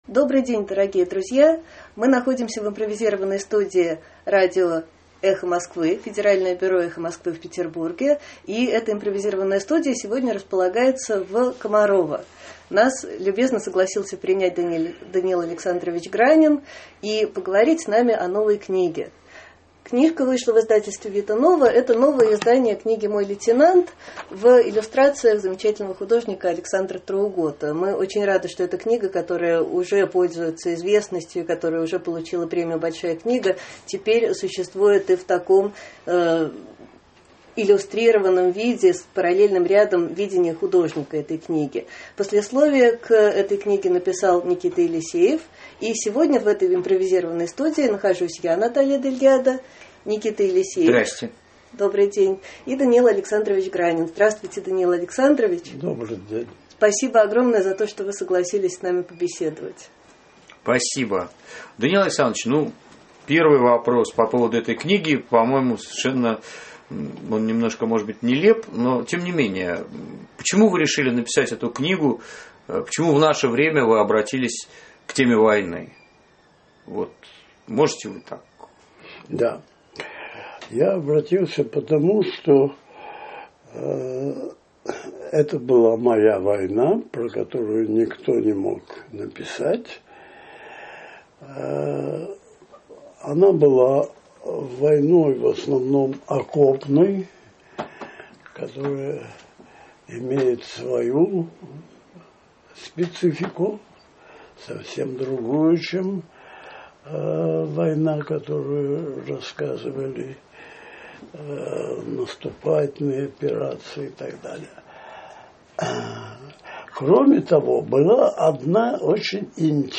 Интервью
Мы находимся в импровизированной студии радио «Эхо Москвы», федеральное бюро «Эхо Москвы» в Петербурге. И эта импровизированная студия сегодня располагается в Комарово.